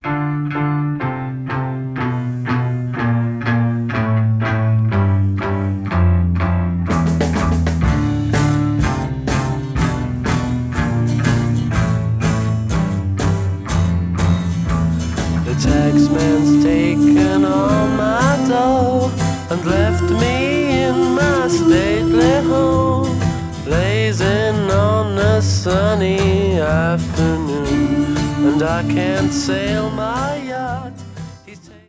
The hiss (wtf?)